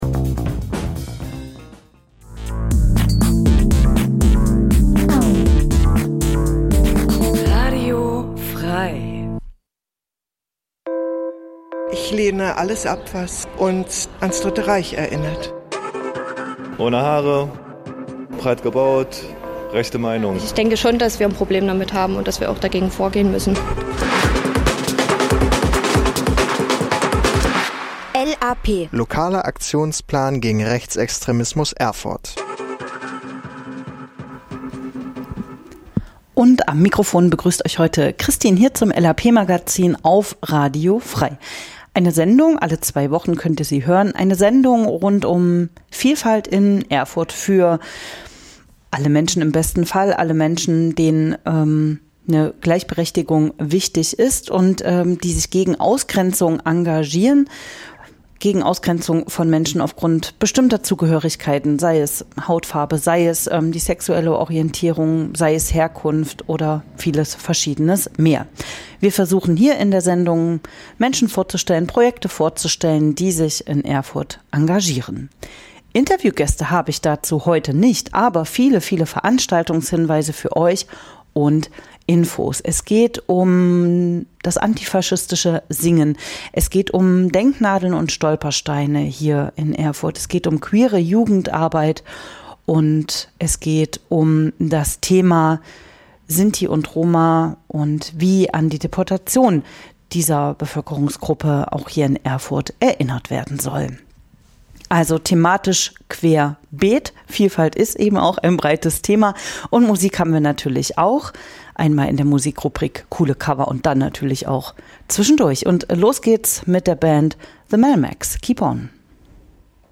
DenkNadeln und Stolpersteine erinnern an Menschen aus Erfurt, die durch den Deutschen Nationalsozialismus zwischen 1933 und 1945 ihr Leben verloren * Audiofeature zur DenkNadel in der Meister-Eckehart-Strasse 1